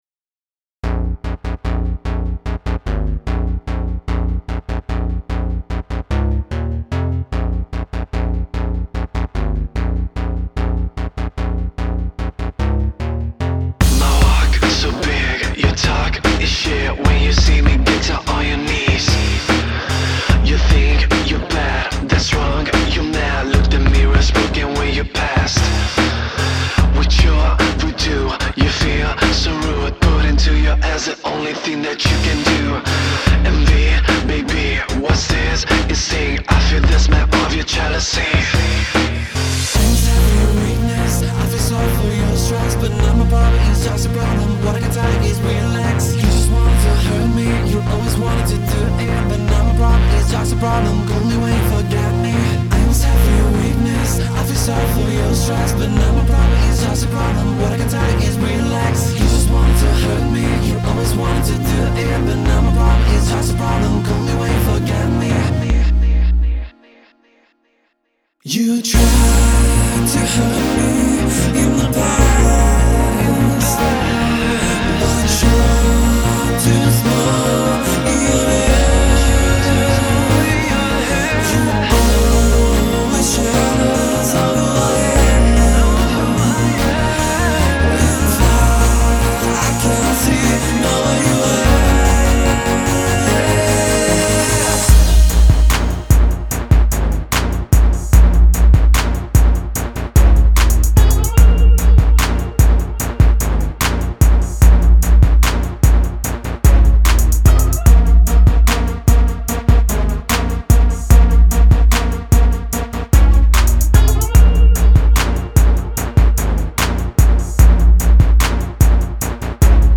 Genere: pop